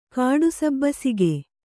♪ kāḍu sabbasige